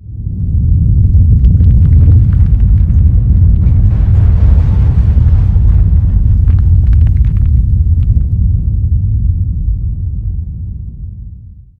earthquake_old.ogg